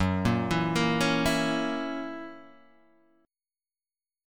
F# 7th